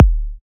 edm-kick-03.wav